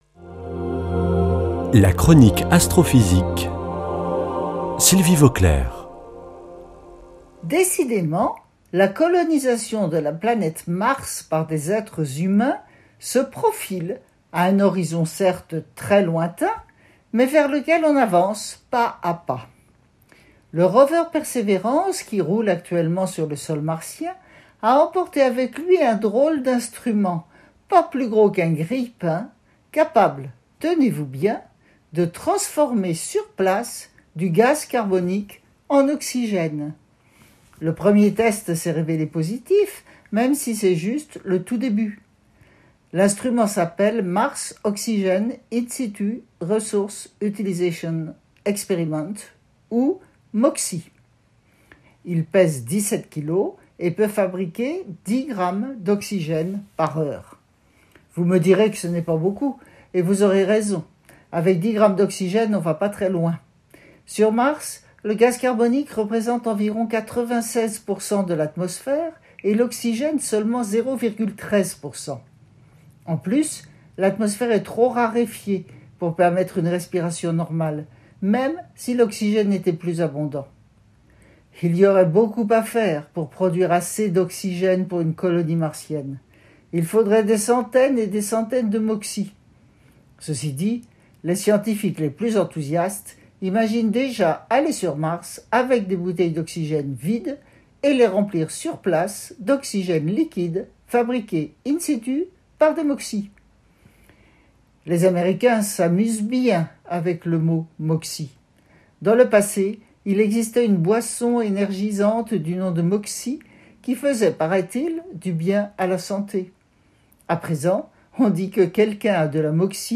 Astrophysicienne